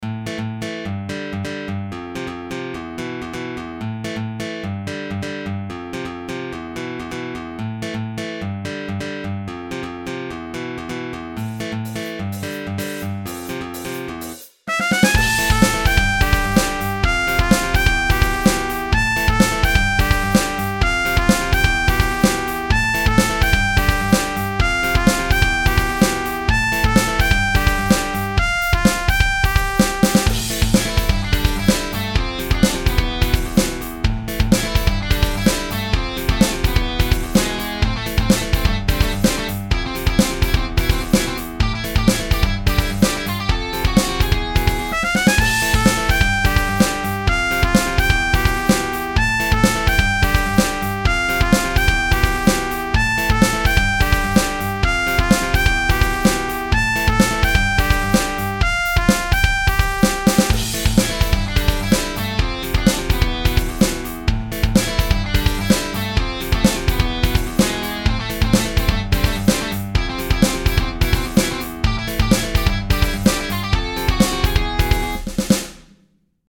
i do! i made a battle theme for meximutt for a game im making. should i post it?
meximutt-battle.mp3 📥 (2.33 MB)
lwky kinda goes hard needs better instruments